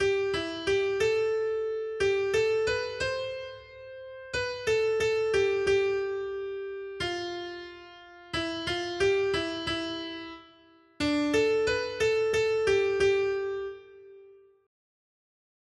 Noty Štítky, zpěvníky ol159.pdf responsoriální žalm Žaltář (Olejník) 159 Skrýt akordy R: Budu tě chválit mezi národy, Pane! 1.